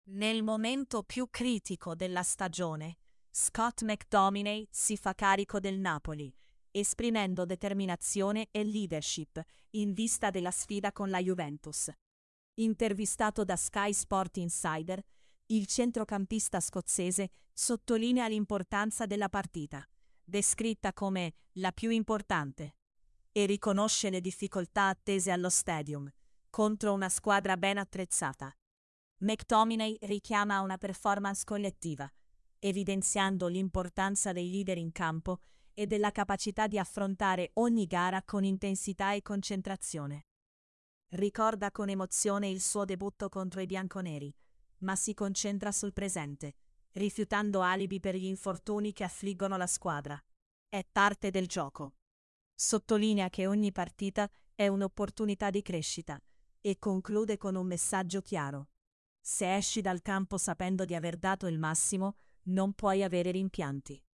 mctominay-carica-il-napoli-con-la-juve-e-la-partita-piu-importante-tts-1.mp3